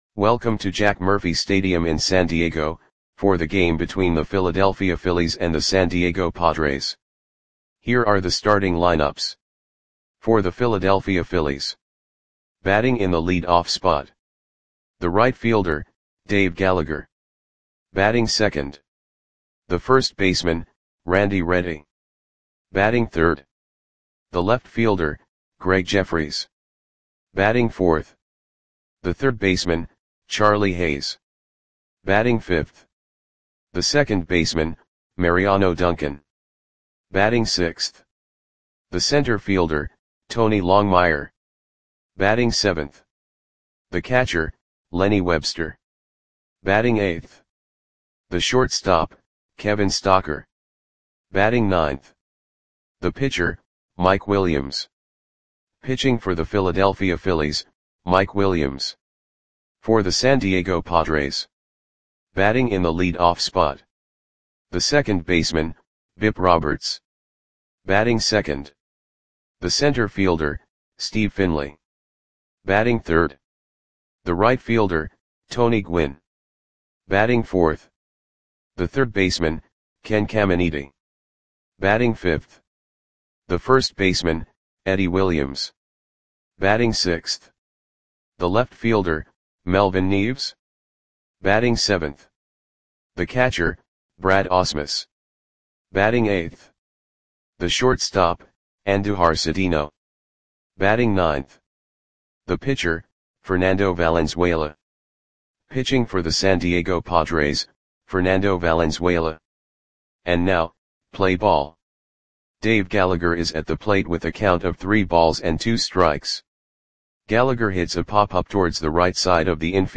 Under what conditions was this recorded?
Lineups for the San Diego Padres versus Philadelphia Phillies baseball game on June 6, 1995 at Jack Murphy Stadium (San Diego, CA).